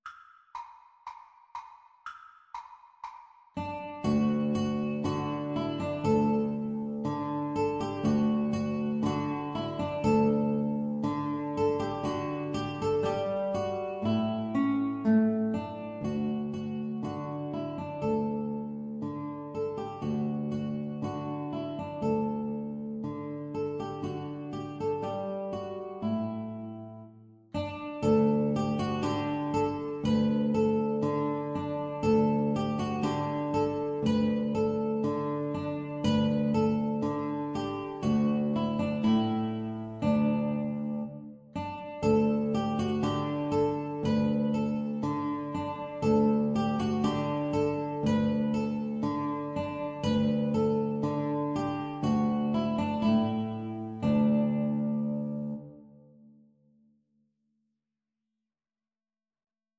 D major (Sounding Pitch) (View more D major Music for Guitar Trio )
Allegro (View more music marked Allegro)
Classical (View more Classical Guitar Trio Music)